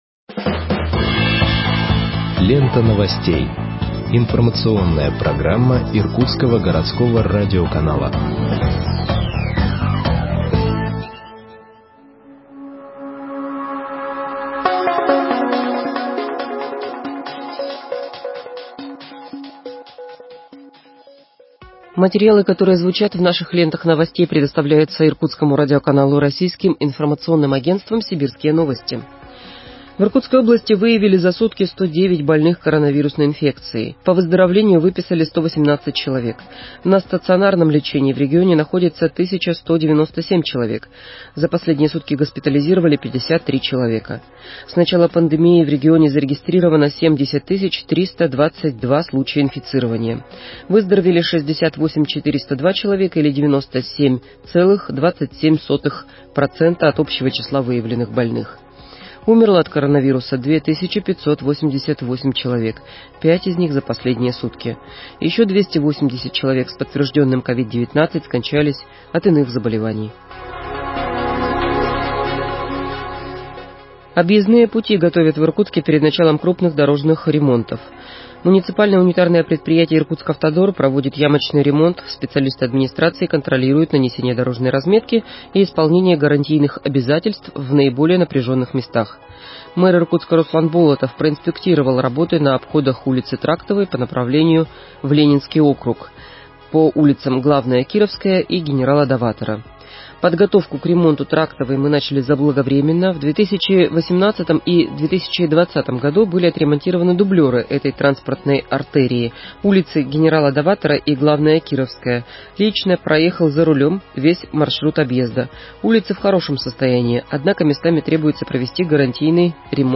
Выпуск новостей в подкастах газеты Иркутск от 03.06.2021 № 2